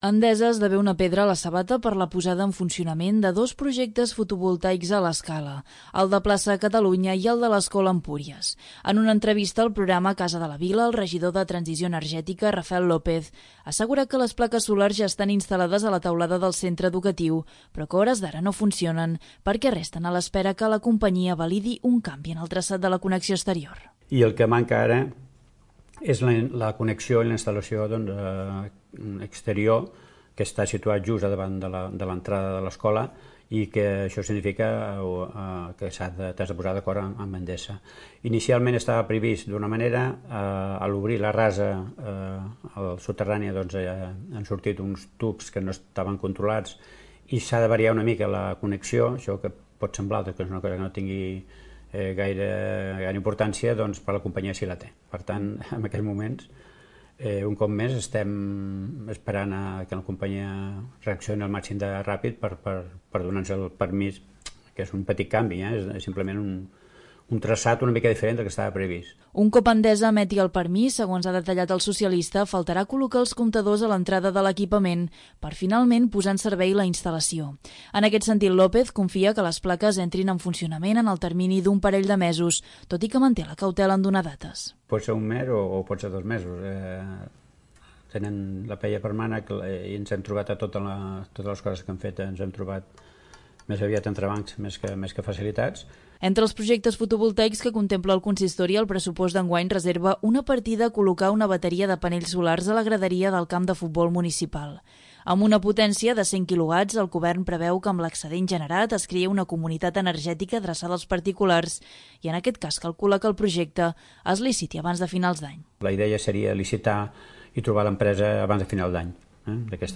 En una entrevista al programa 'Casa de la Vila', el regidor de transició energètica, Rafel López, ha assegurat que les plaques solars ja estan instal·lades a la teulada del centre educatiu, però que a hores d'ara, no funcionen, perquè resten a l'espera que la companyia validi un canvi en el traçat de la connexió exterior.